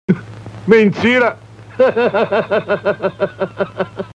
Risada "Mentira Hahaha"
Homem fala "mentira!" e solta uma risada hahaha.
risada-mentira-hahaha.mp3